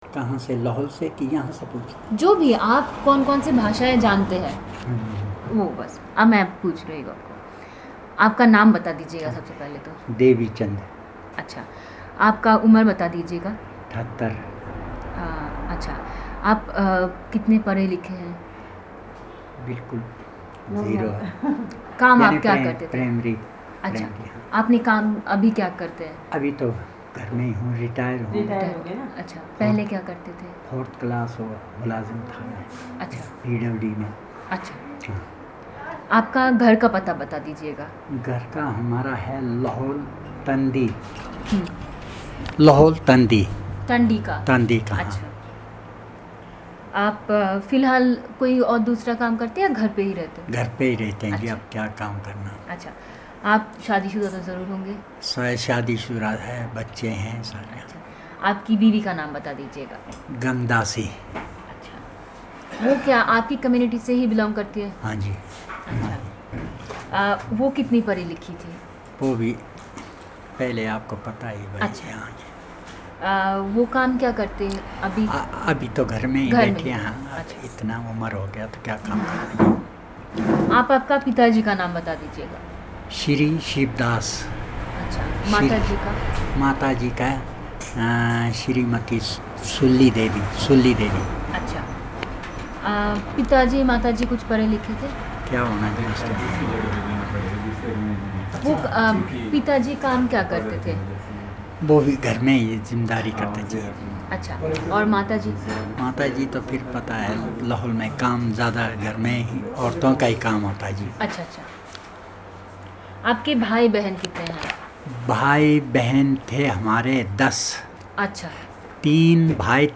Personal narrative on personal and social information in Chinali